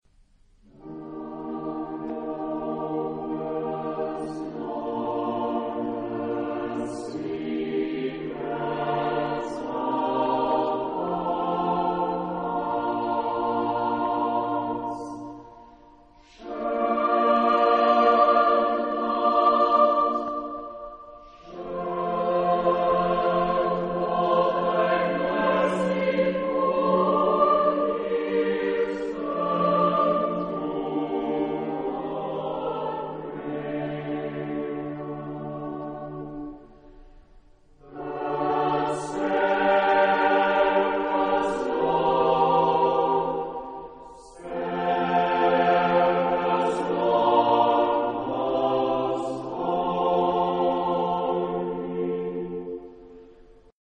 Genre-Stil-Form: geistlich ; Hymne (weltlich)
Chorgattung: SATB  (4 gemischter Chor Stimmen )
Tonart(en): B-dur